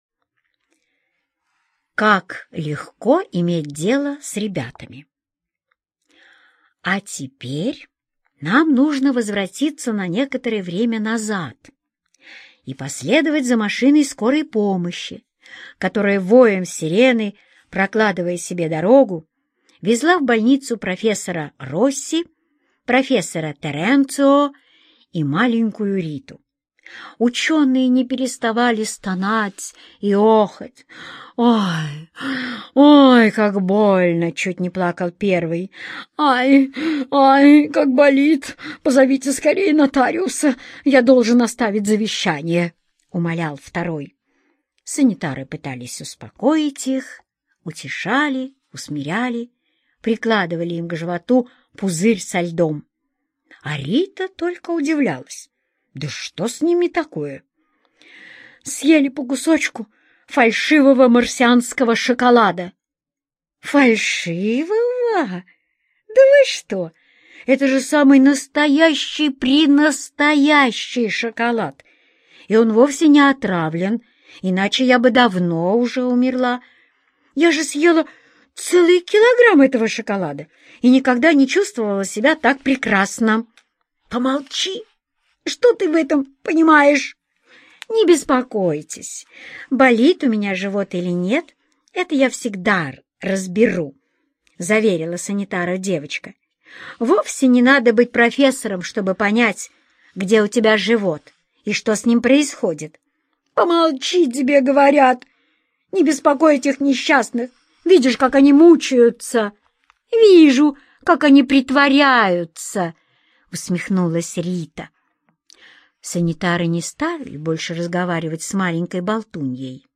Как легко иметь дело с ребятами - аудиосказку Родари Д. Однажды девочка Рита объясняла врачам и ученым, что у нее ничего не болит.